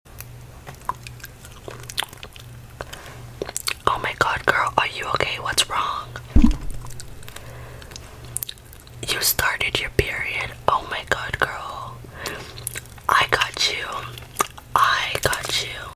Her voice is very relaxing!